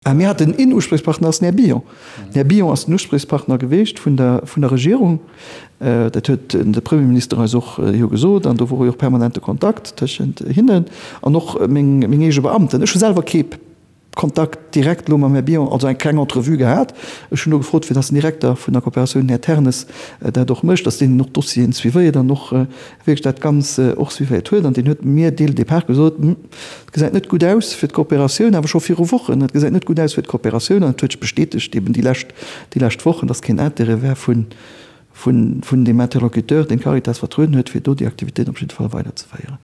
D'Ausso vum Xavier Bettel